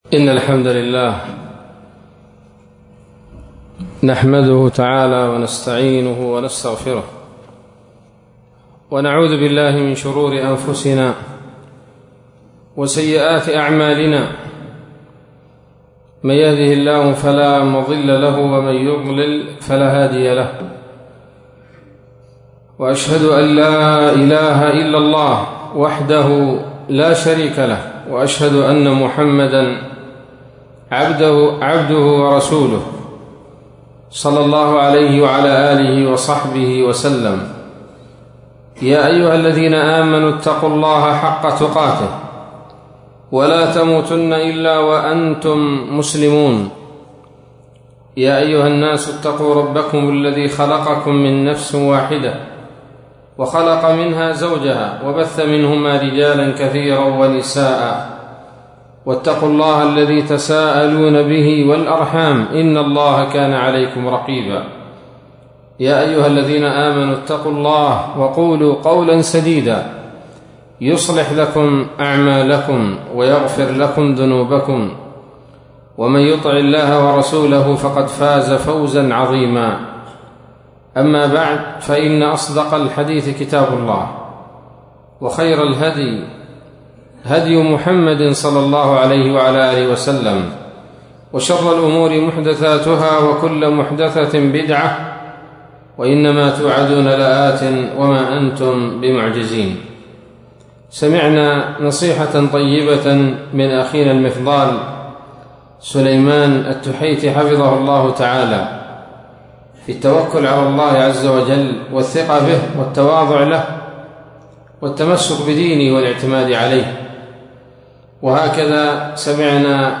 محاضرة قيمة
ليلة السبت 22 رجب 1442هـ، بمنطقة حبيل جبر - ردفان - لحج